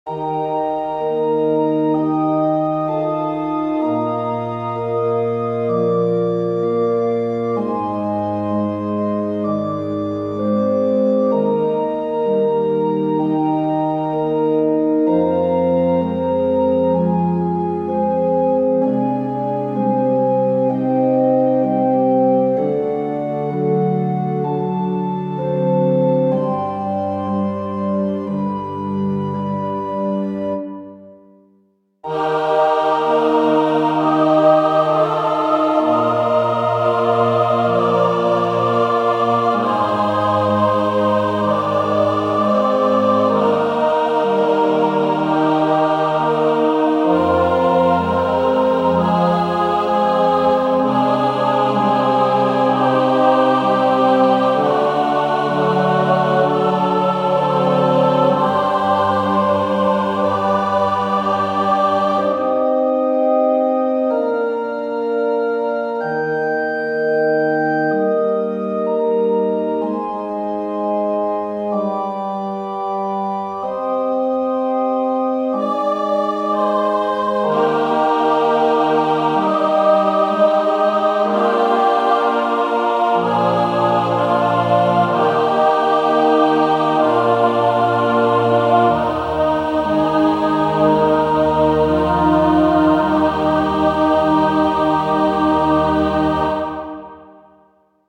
ogg(R) 荘厳 オルガン 壮大
包み込むやわらかな空気。静かなオルガンから荘厳なコーラス。